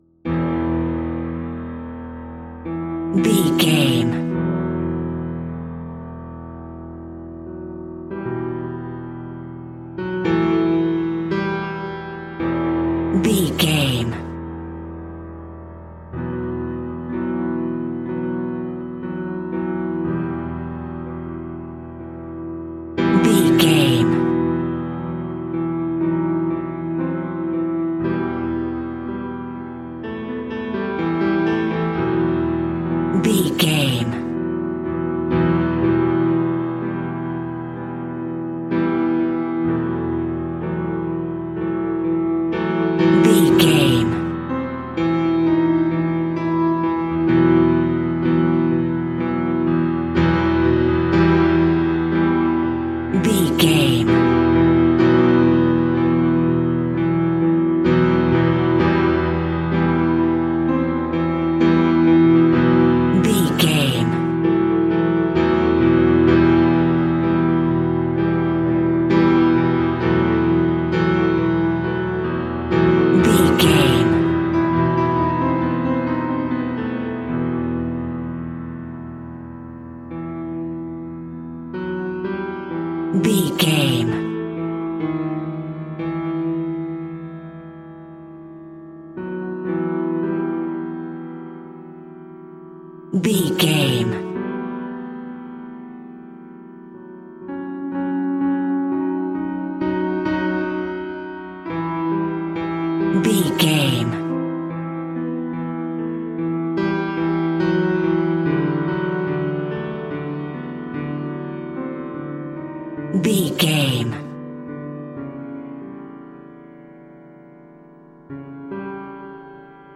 Piano Muder Music.
Thriller
Aeolian/Minor
Slow
ominous
dark
disturbing
haunting
eerie
melancholy